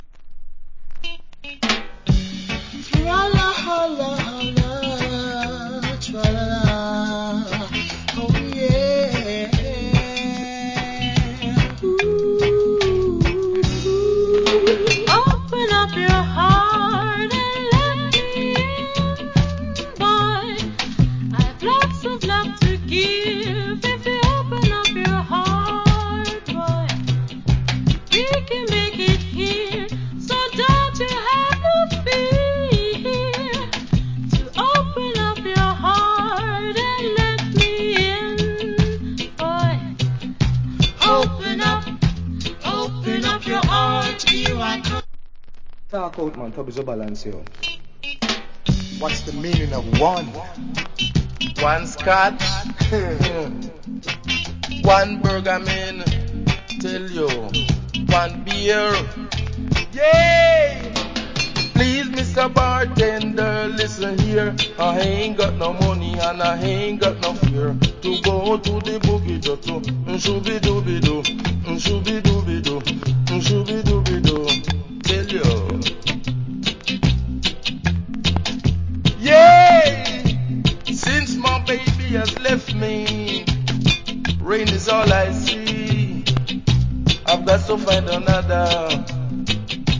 Nice Female Regga Vocal.